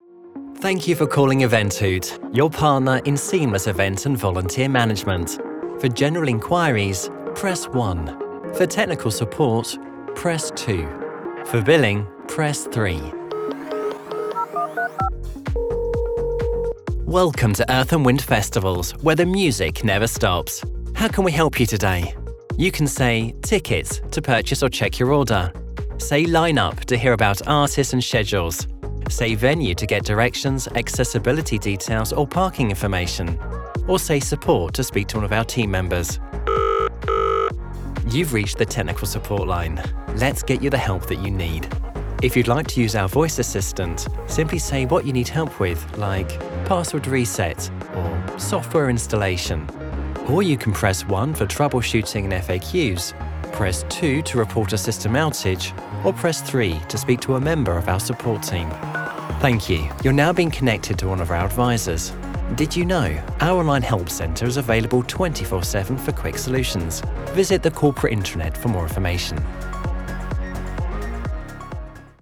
IVR on-hold messaging
Gender: Male
Description of voice: I have a neutral British accent. My voice has a fresh, clear, measured and self-assured tone. Friendly, but authoritative if needed!
Home Recording Studio
Microphones: Neumann TLM 102